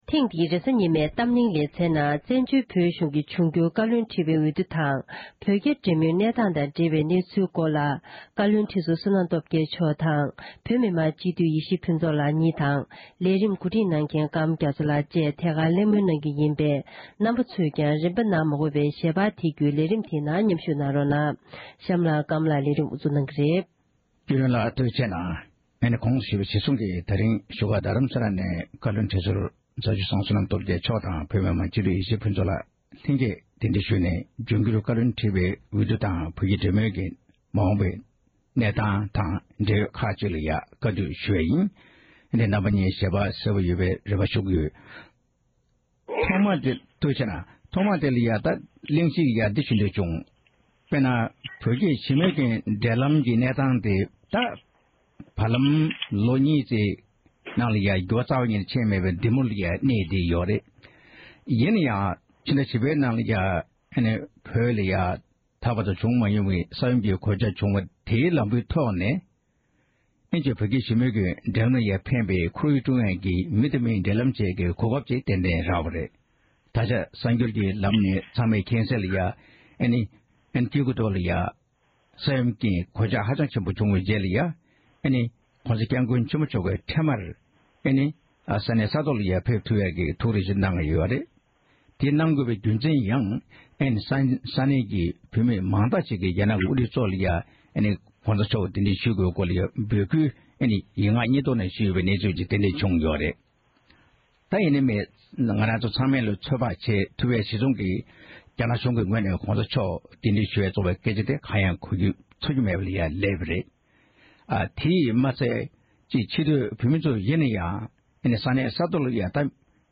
༄༅༎དེ་རིང་གི་རེས་གཟའ་ཉི་མའི་གཏམ་གླེང་གི་ལེ་ཚན་ནང་འབྱུང་འགྱུར་བཀའ་བློན་ཁྲི་པའི་འོས་བསྡུ་དང་བོད་རྒྱ་འབྲེལ་མོལ་གྱི་གནས་སྟངས་དང་འབྲེལ་ཡོད་གནས་ཚུལ་སྐོར་བཀའ་བློན་ཁྲི་ཟུར་བསོད་ནམས་སྟོབས་རྒྱལ་མཆོག་དང་།